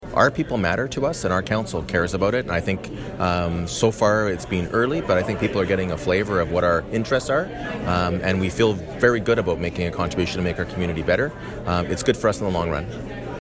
Belleville Mayor Mitch Panciuk speaks at the OLG Recognition Event on March 21, 2019 at the Quinte Sports and Wellness Centre.